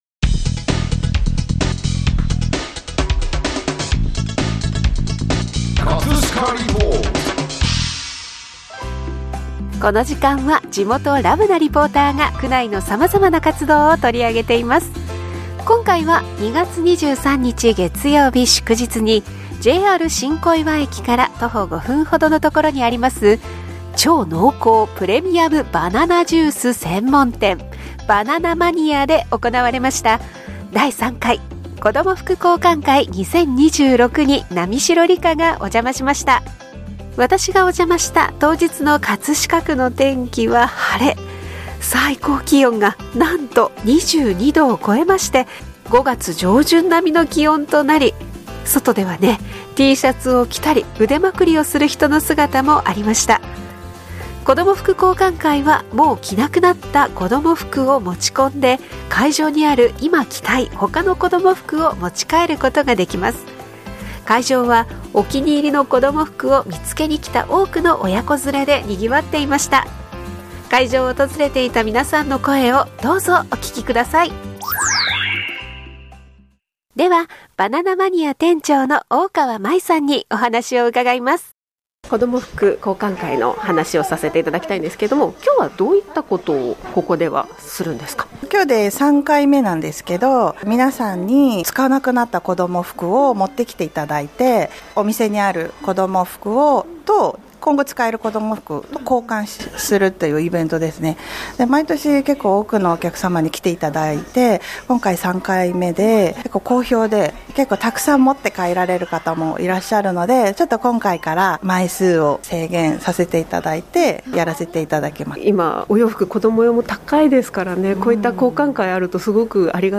【葛飾リポート】 葛飾リポートでは、区内の様々な活動を取り上げています。